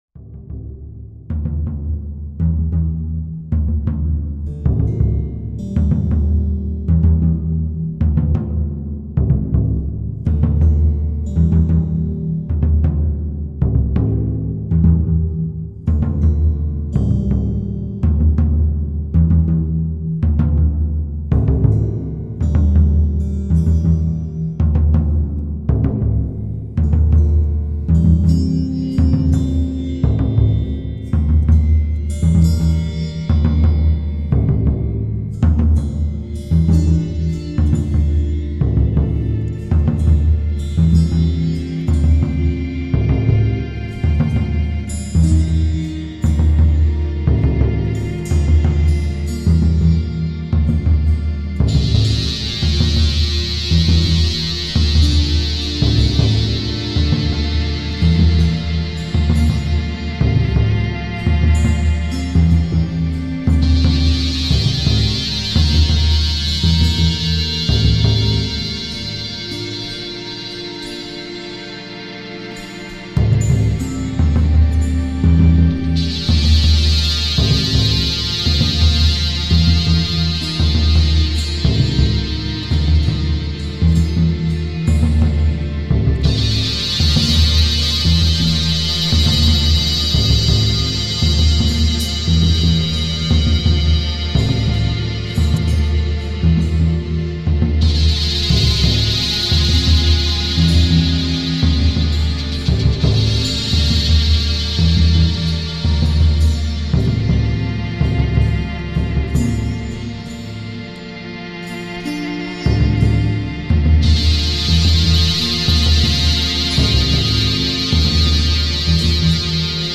The music unfolds in a rough and deep manner
treated guitars and spinet
percussion
• Genre: Experimental / Avant-Garde / Drone